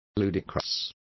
Complete with pronunciation of the translation of ludicrous.